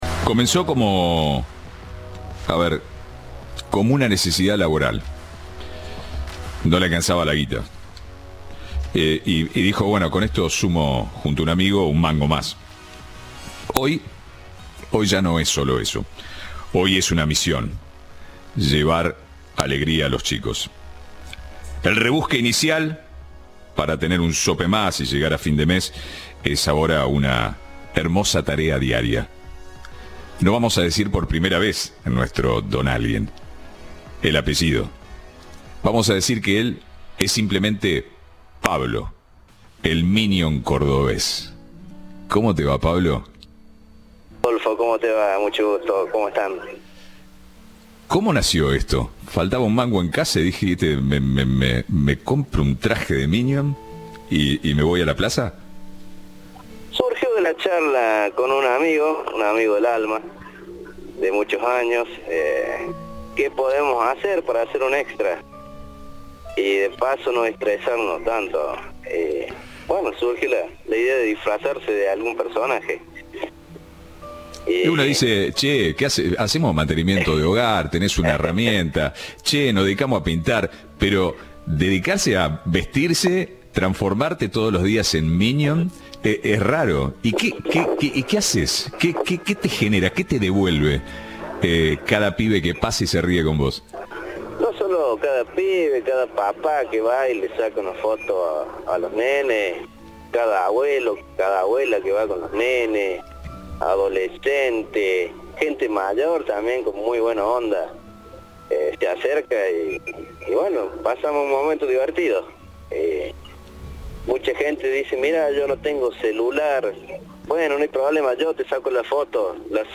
Entrevista de Rodolfo Barili.